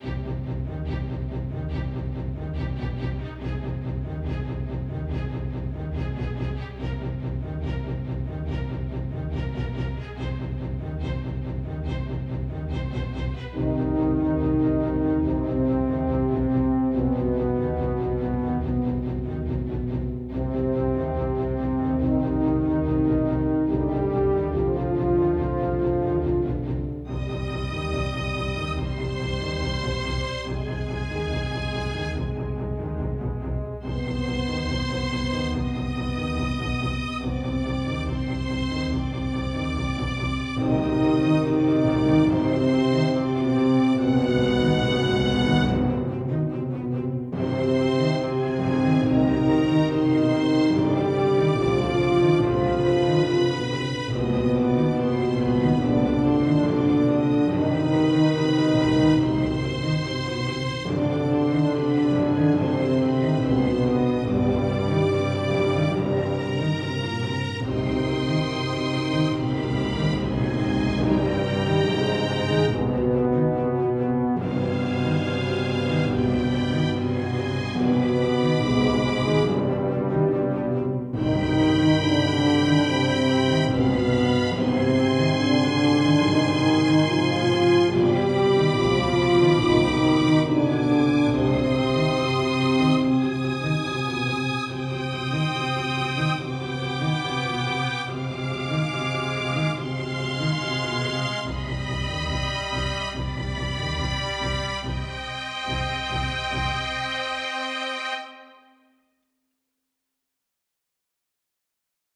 🎵 Trilha Action Cinematic Music